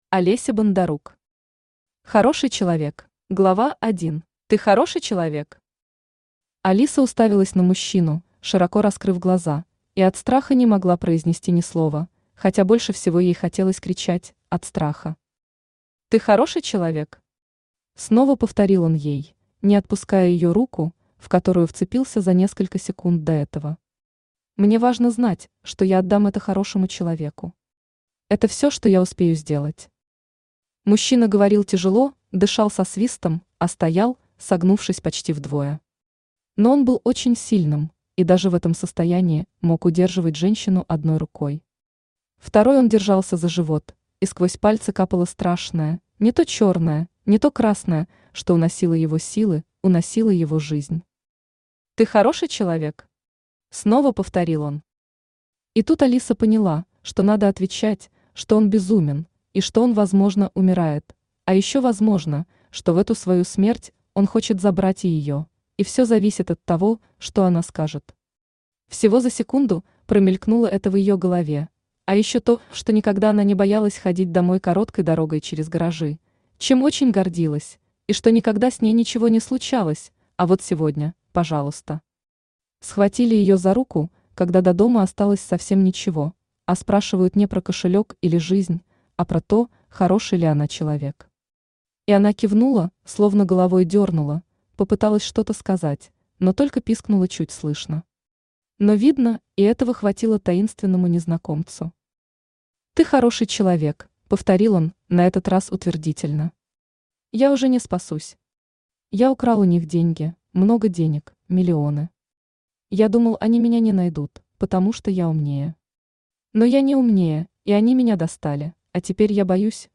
Аудиокнига Хороший человек | Библиотека аудиокниг
Aудиокнига Хороший человек Автор Олеся Николаевна Бондарук Читает аудиокнигу Авточтец ЛитРес.